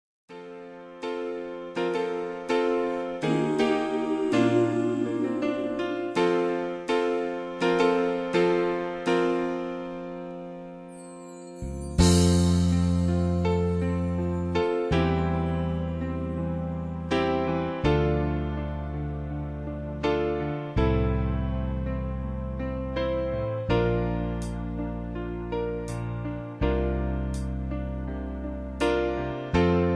karaoke , backing tracks